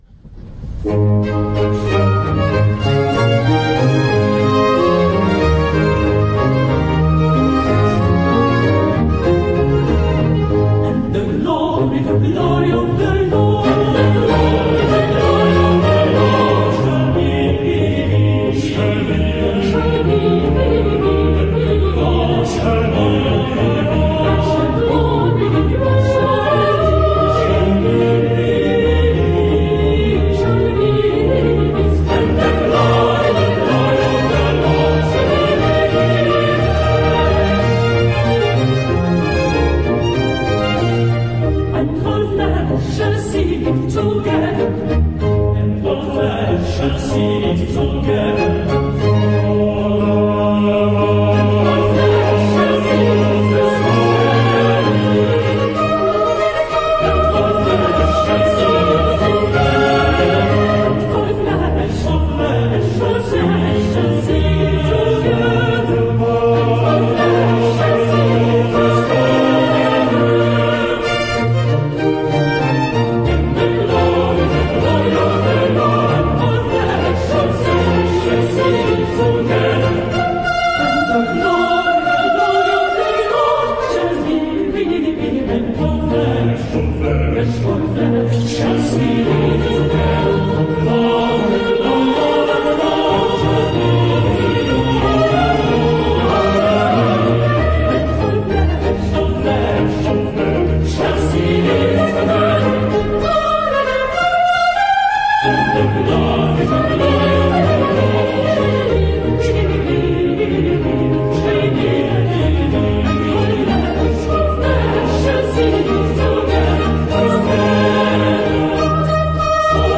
Kázeň